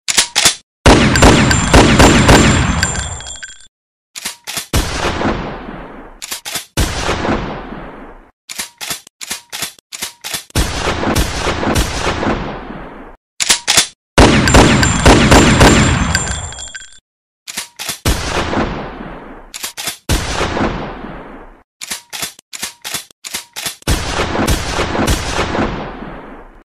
Barulho de Tiro para Dar Susto
Categoria: Sons de armas de combate
Com som realista e alta qualidade, o Barulho de Tiro garante boas risadas e reações inesperadas.
barulho-de-tiro-para-dar-susto-pt-www_tiengdong_com.mp3